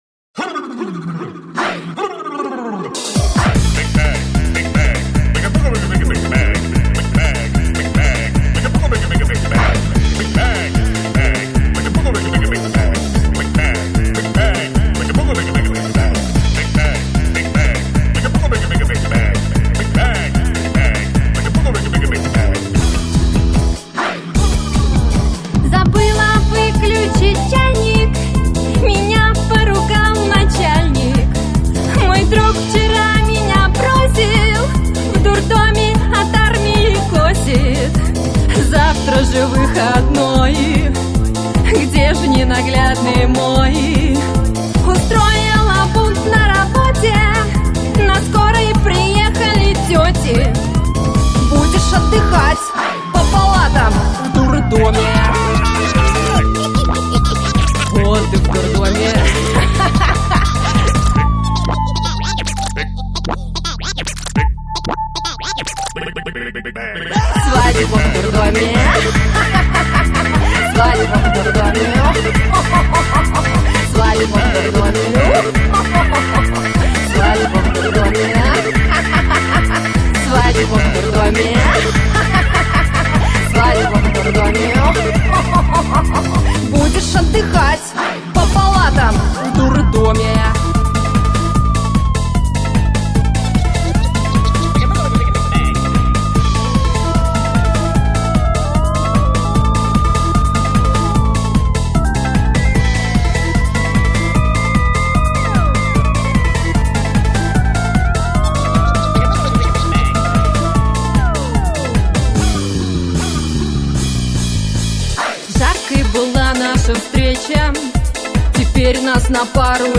Музыкальный хостинг: /Танцевальная